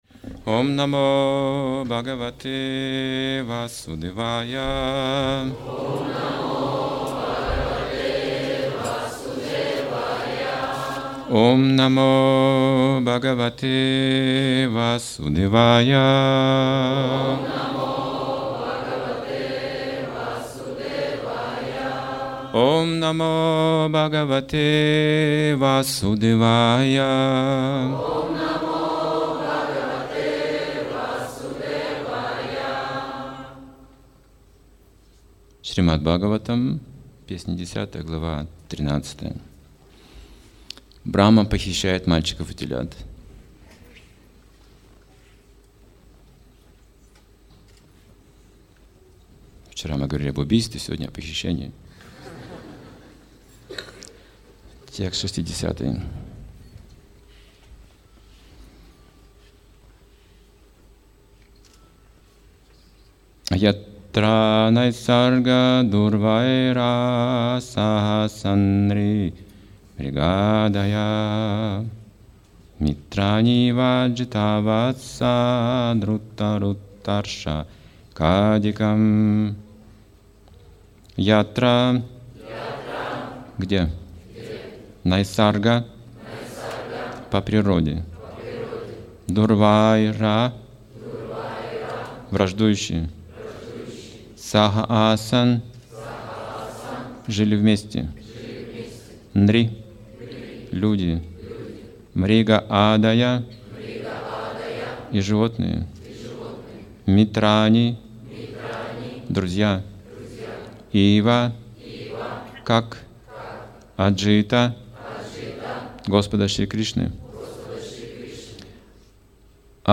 Лекции